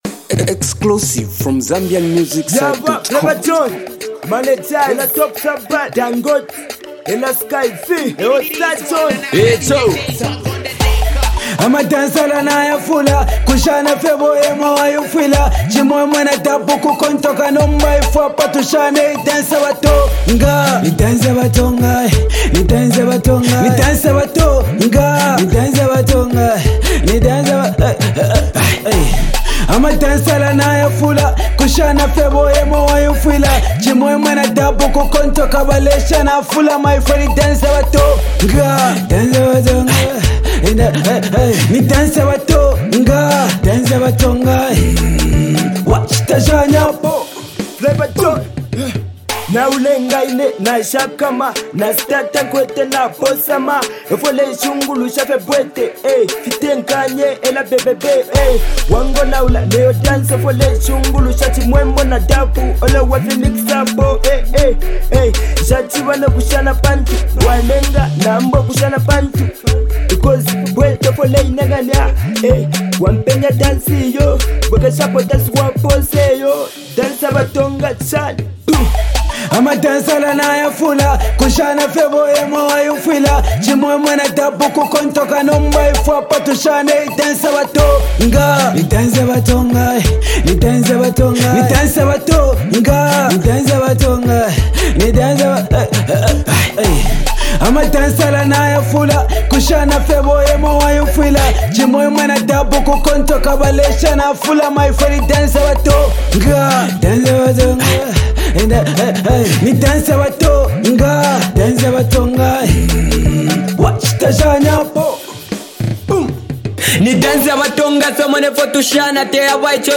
an amazing danceable tune